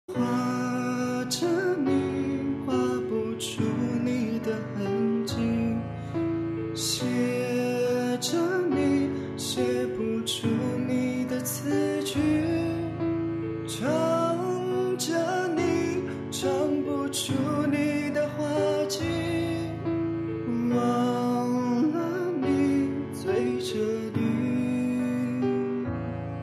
M4R铃声, MP3铃声, 华语歌曲 19 首发日期：2018-05-14 10:09 星期一